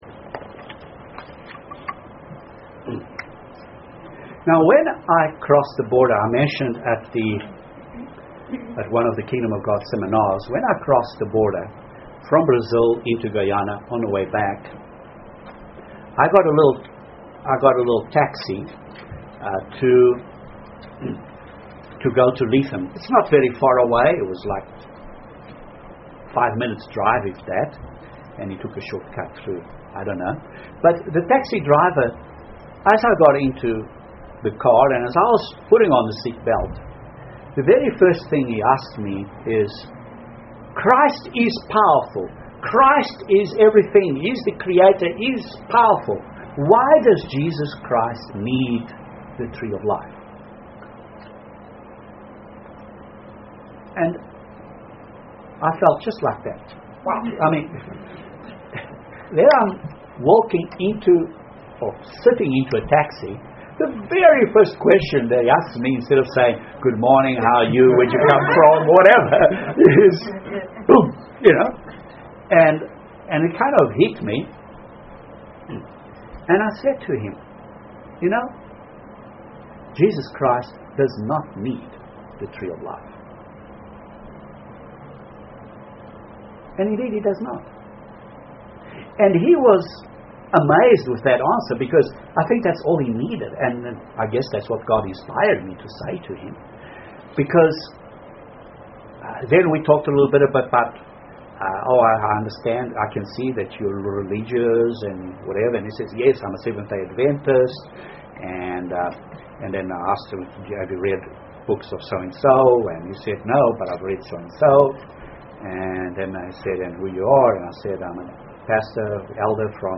However there is a deeper question which should be asked, and that is "Why the tree of life?" This sermon analyses that theme.